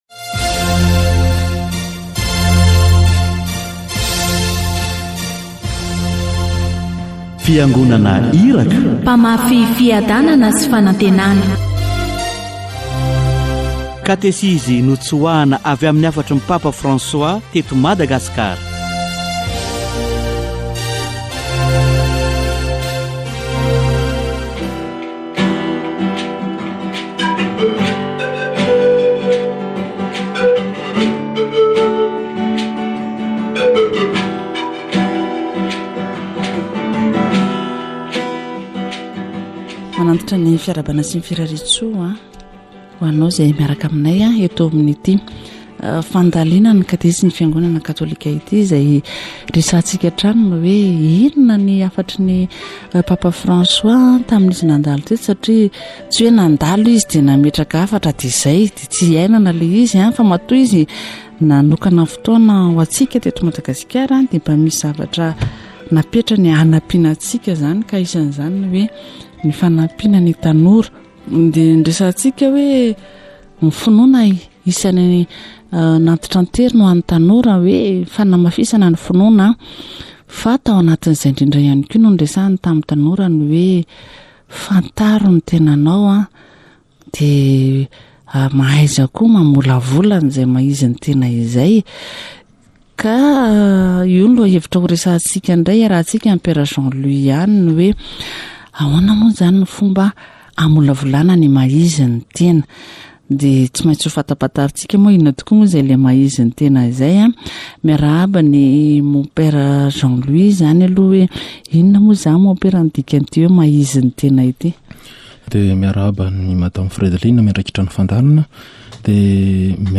Katesizy momba ny famolavolana ny maha izy ny tena